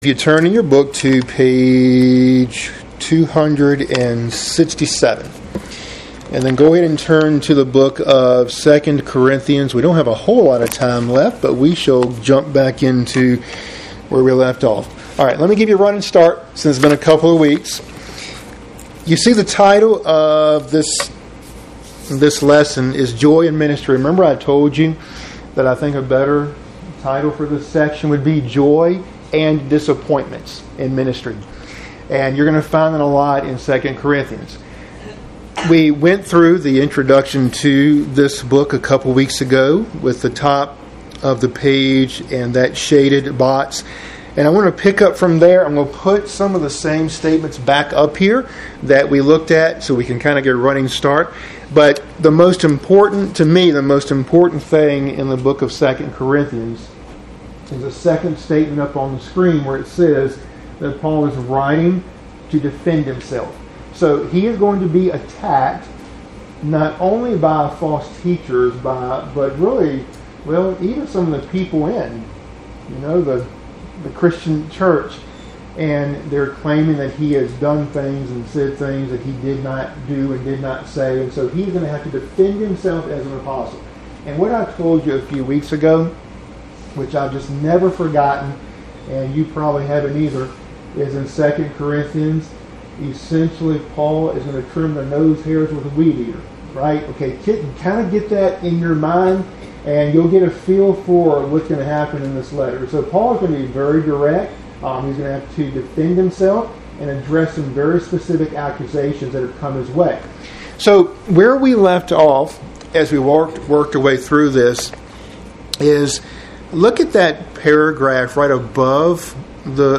Midweek Bible Study – Lesson 54 (cont.)